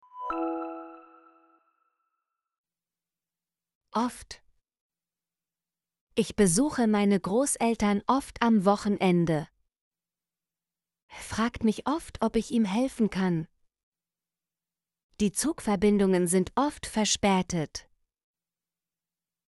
oft - Example Sentences & Pronunciation, German Frequency List